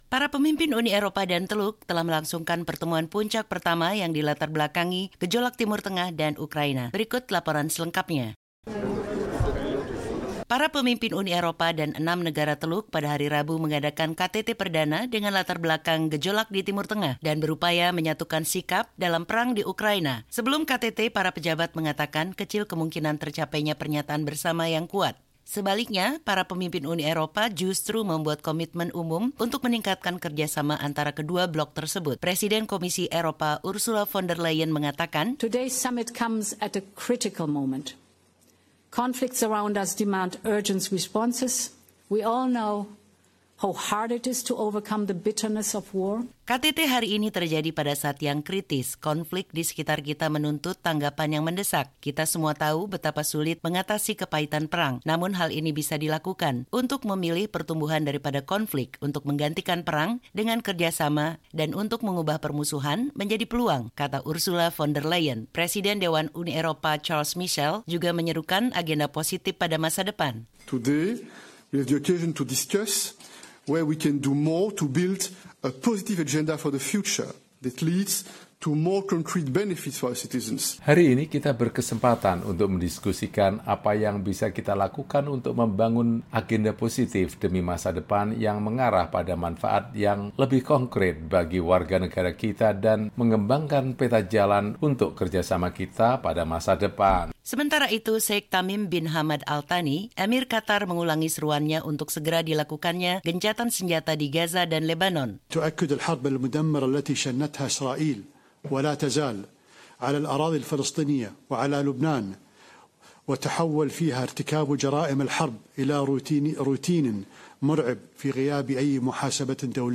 Para pemimpin Uni Eropa dan Teluk telah melangsungkan pertemuan puncak pertama yang dilatarbelakangi gejolak Timur Tengah dan Ukraina. Berikut laporan selengkapnya.